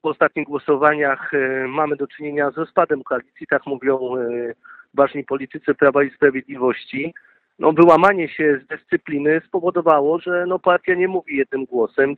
Z wypowiedzi Wojciecha Kossakowskiego – posła PiS z Ełku możemy wywnioskować, że sprawa jest już przesądzona.
Zapytany o powody potencjalnego rozpadu koalicji ełcki poseł mówił o jedności w Zjednoczonej Prawicy.